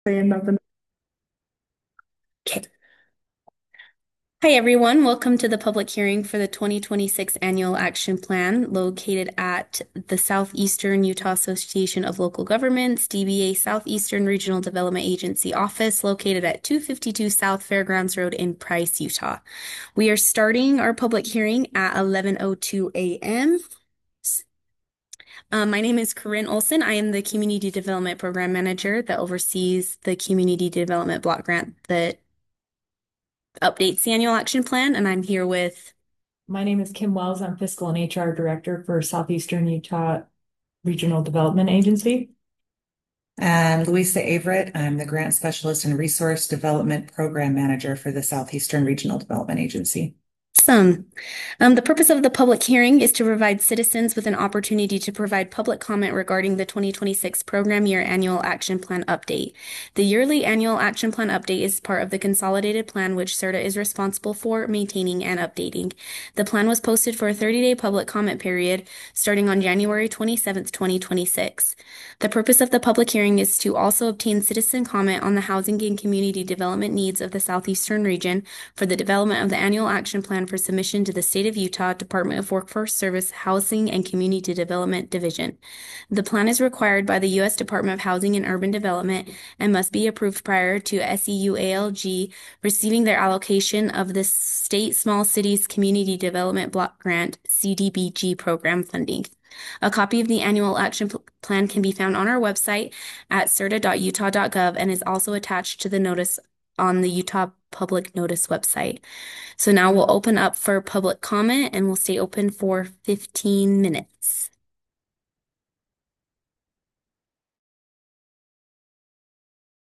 Public Hearing